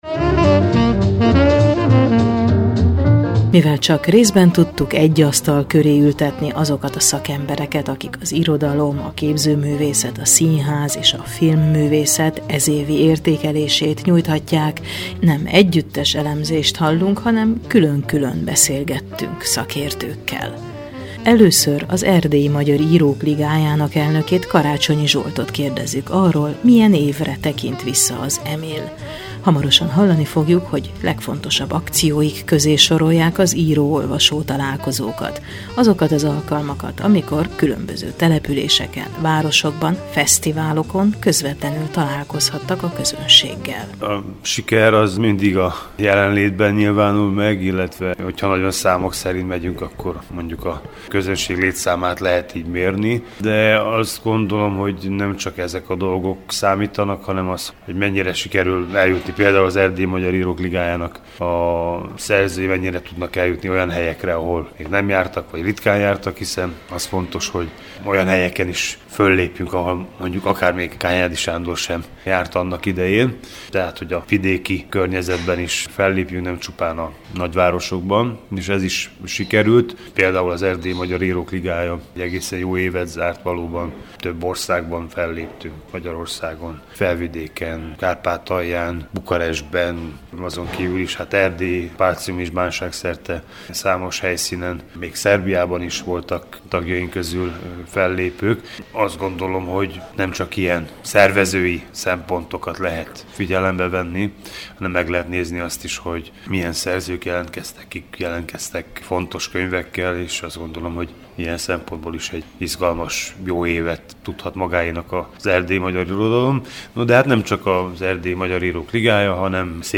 Kulturális év végi beszélgetéseink szereplői: